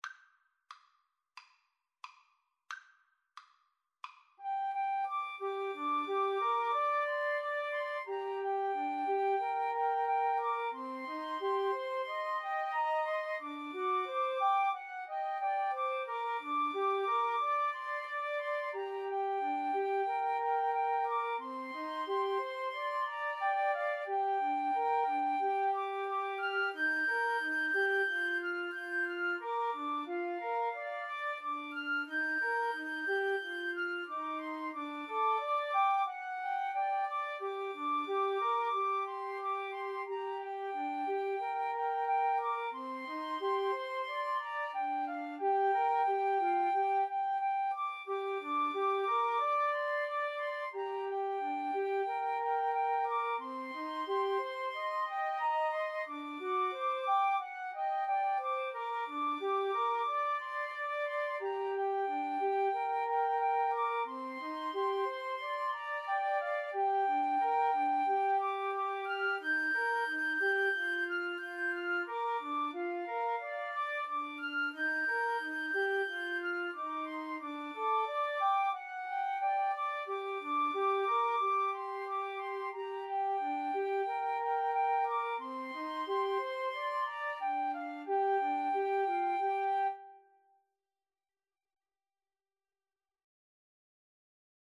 4/4 (View more 4/4 Music)
Andante = c. 90